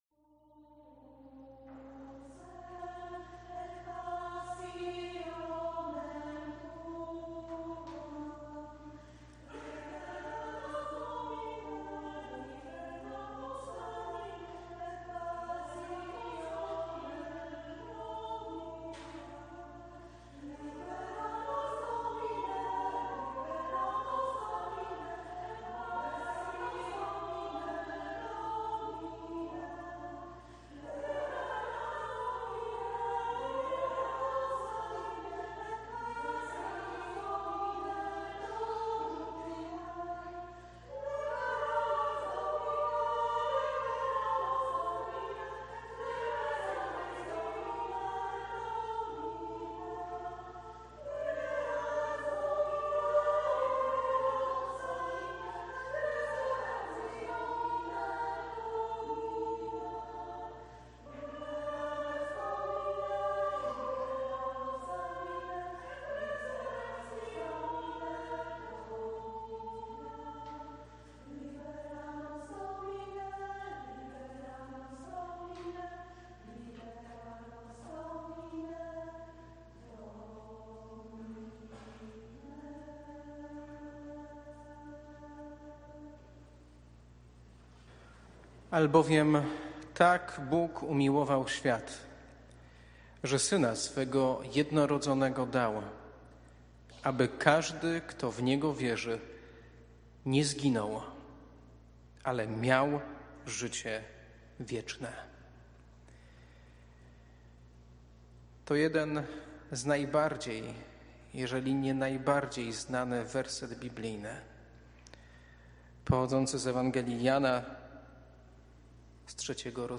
WIELKI PIĄTEK – wieczorne